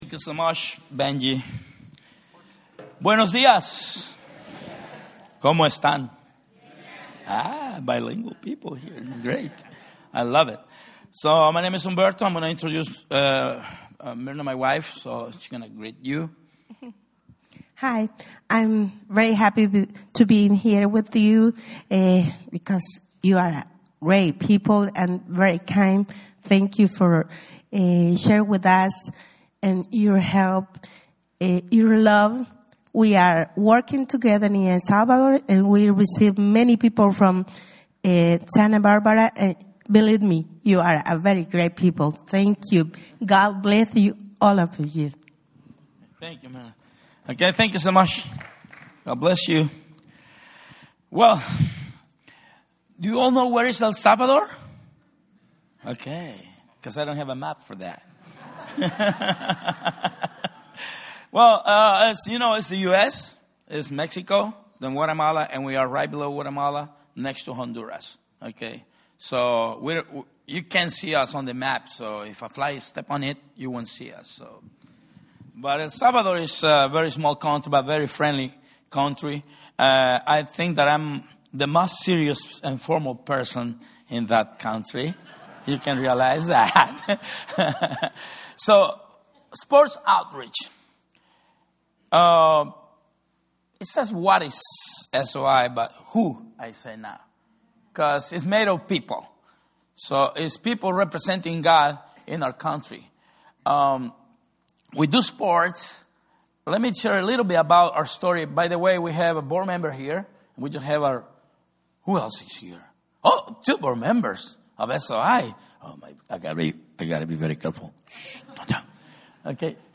Speaker: Guest Preacher | Series: Missions Conference…
Service Type: Special Events